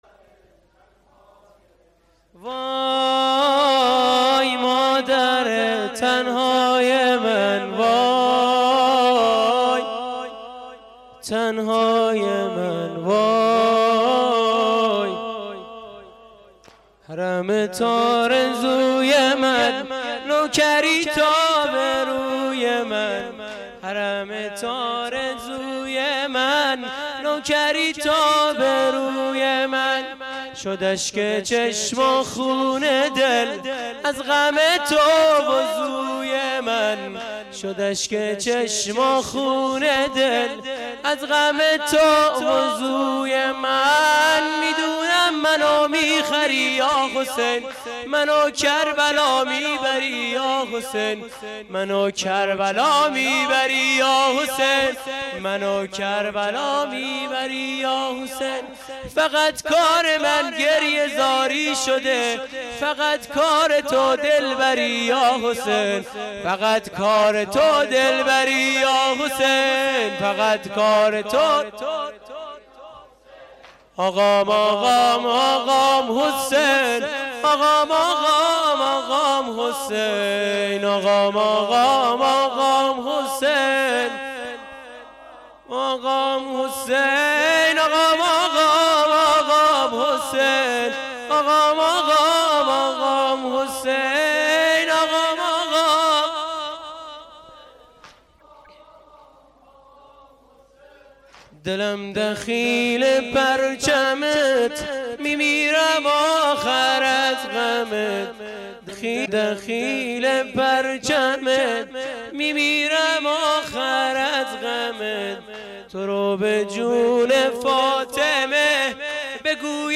سینه زنی واحد
• روستای کرغند, سینه زنی واحد, هیئت ثارالله کرغند